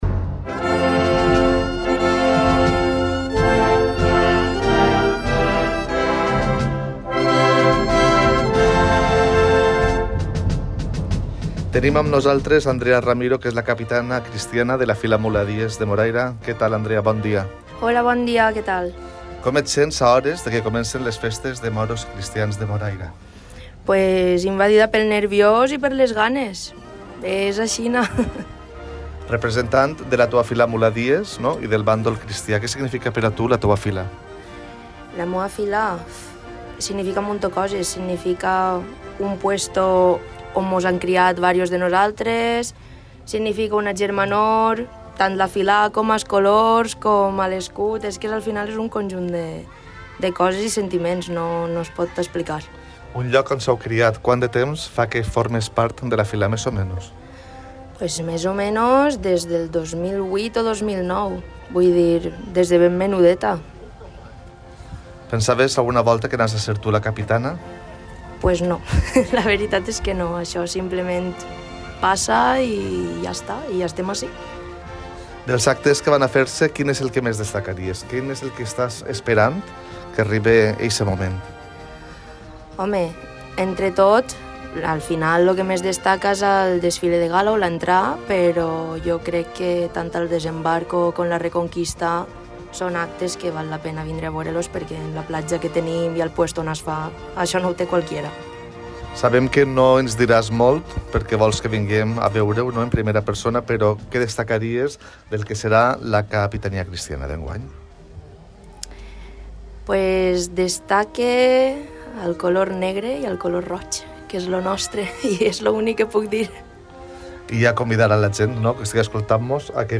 Podcast Entrevistas